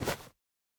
Minecraft Version Minecraft Version 1.21.4 Latest Release | Latest Snapshot 1.21.4 / assets / minecraft / sounds / item / bundle / remove_one2.ogg Compare With Compare With Latest Release | Latest Snapshot